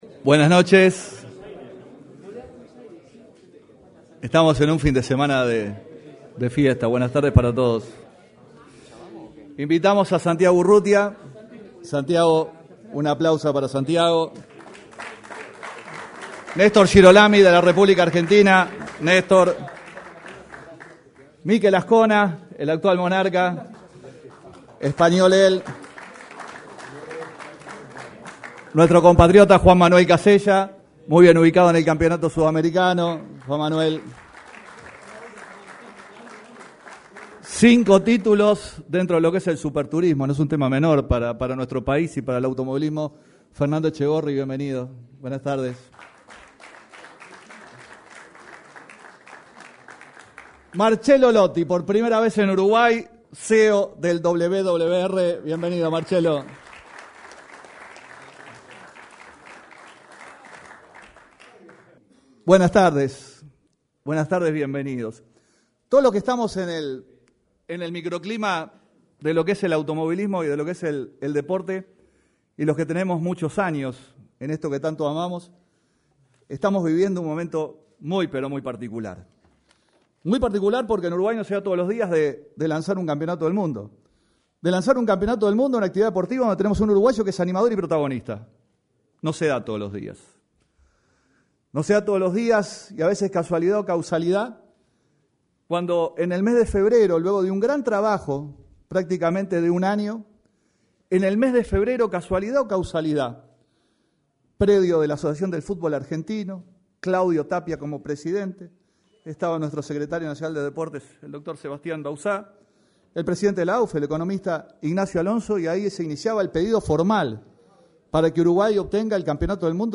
se expresaron en el salon de actos de la Torre Ejecutiva.